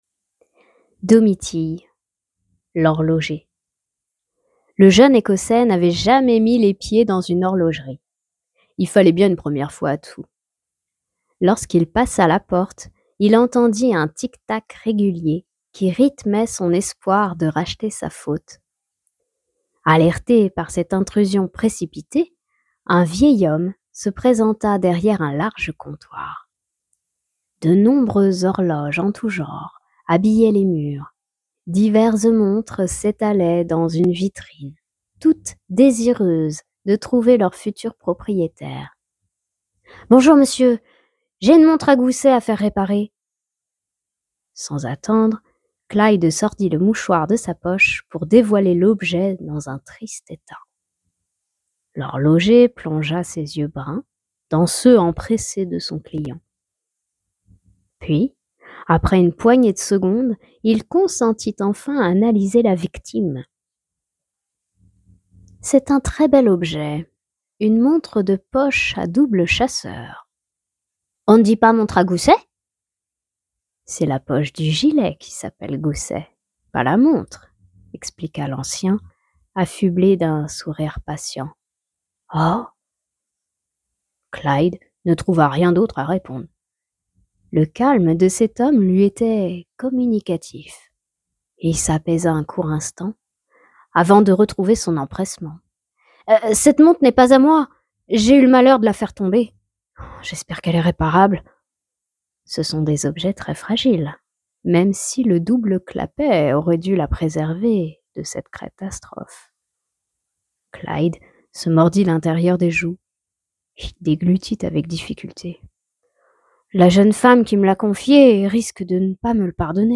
Non seulement le ton y est mais ta voix passe vraiment très bien.